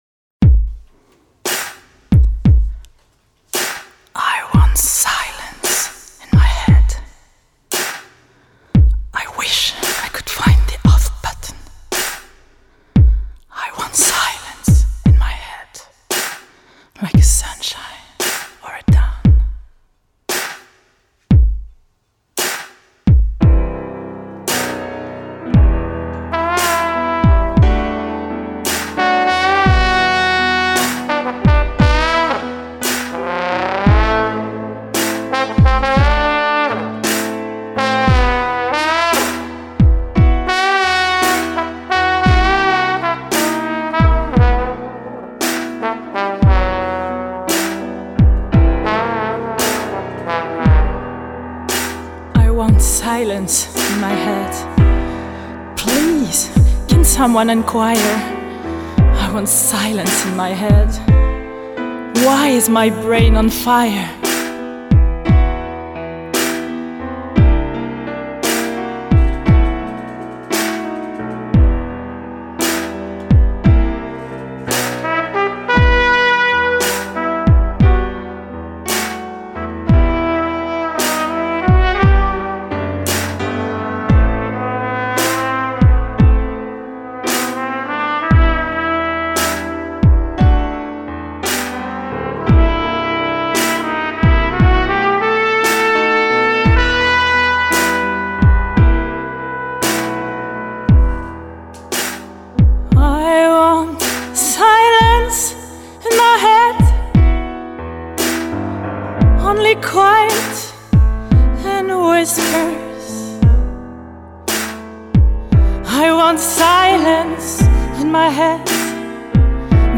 bells